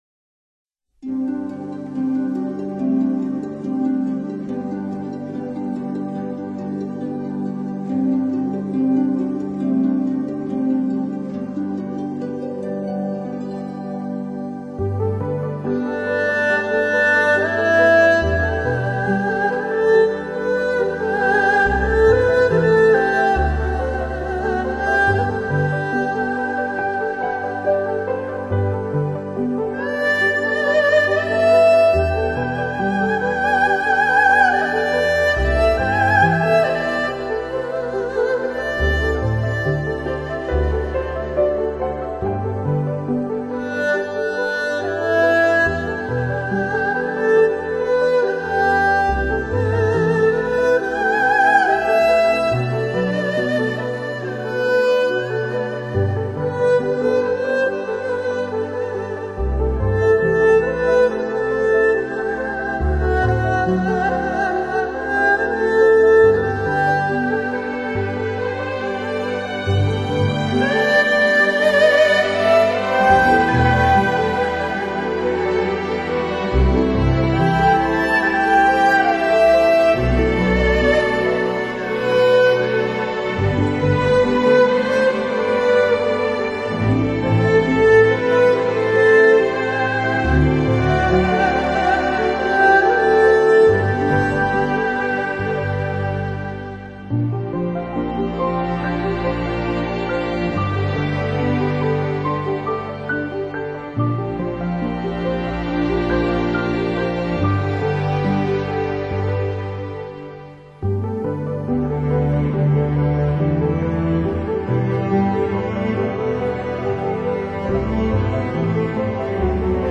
二胡とストリングスによる癒しの旋律